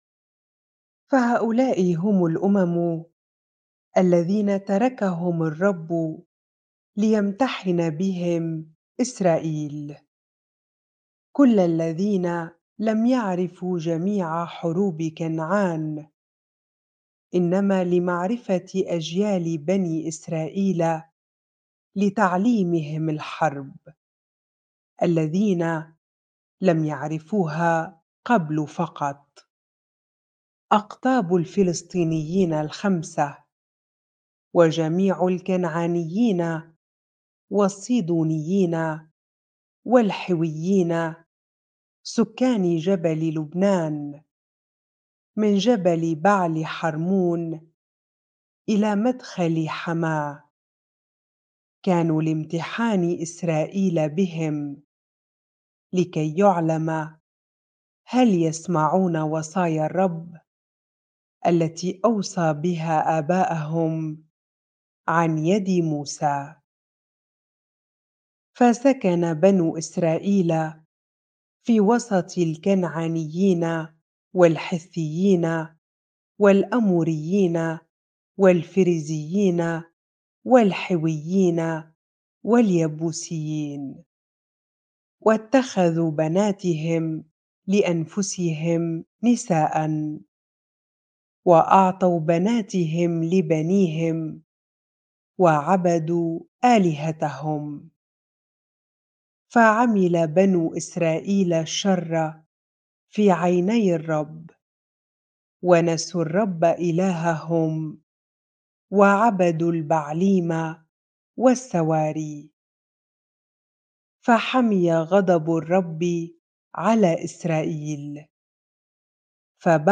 bible-reading-Judges 3 ar